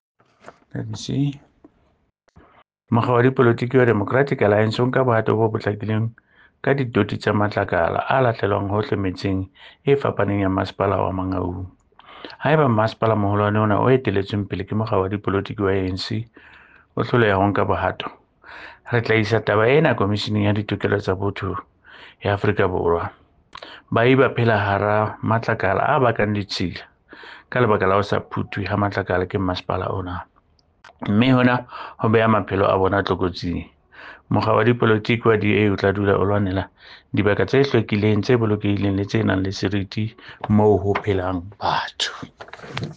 Sesotho soundbites by Cllr Edwin Maliela.